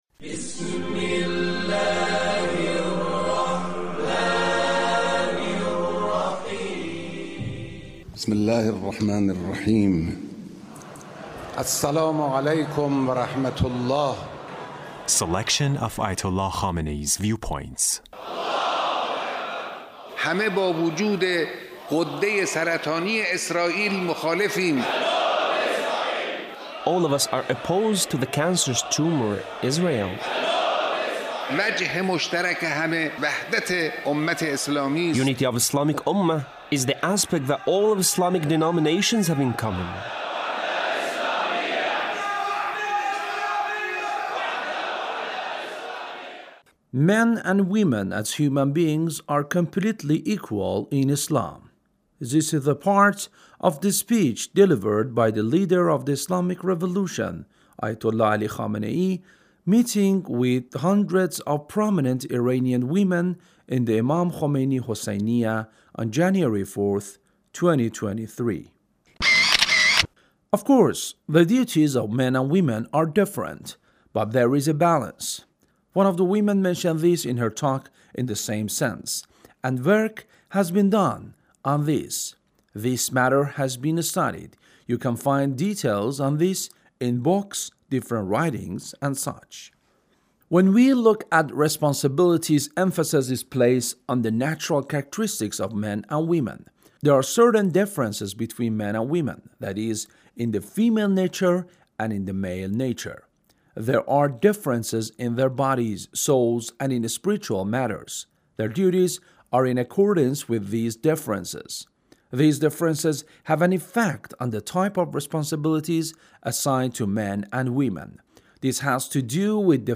Leader's Speech meeting with hundreds of prominent Iranian women